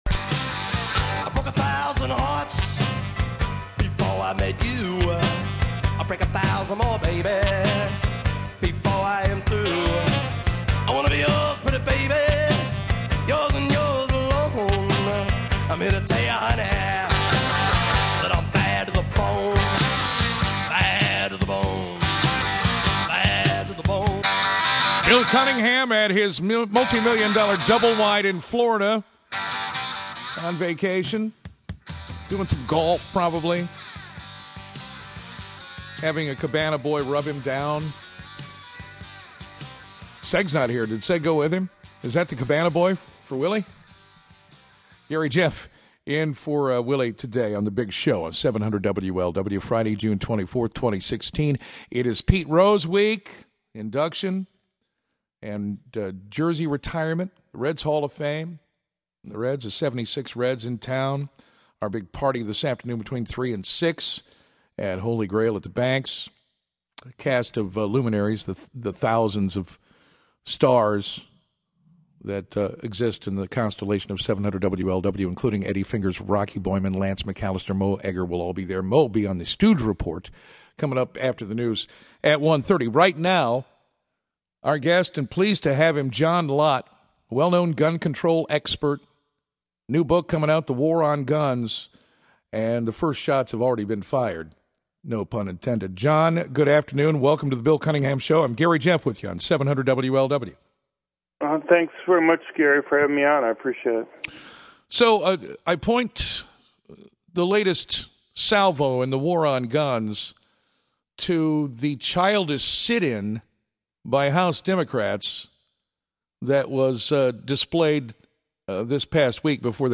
CPRC on The Bill Cunningham Show 700WLW: Gun Control Measures fail in the Senate and sit in by democrats in the house